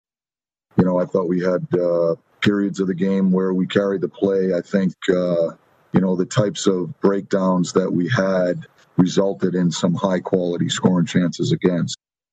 Coach Mike Sullivan says the Penguins had too many defensive breakdowns.